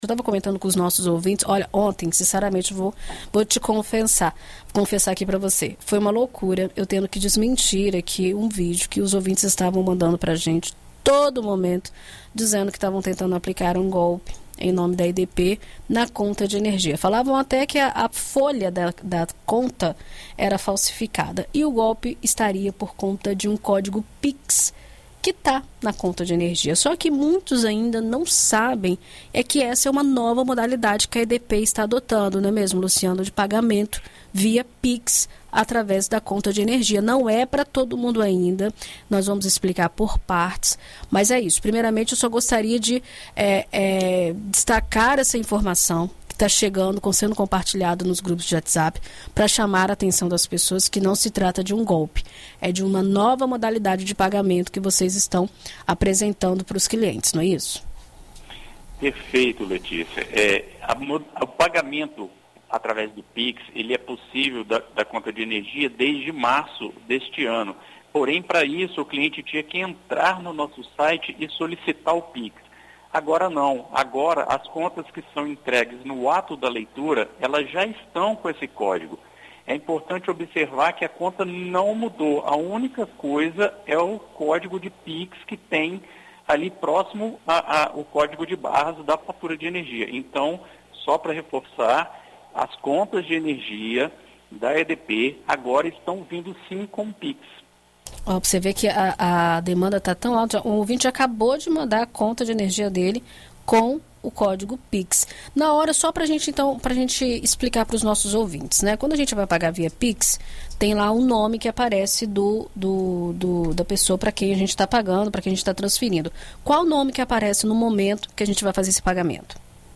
Em entrevista à BandNews FM Espírito Santo nesta sexta-feira (19)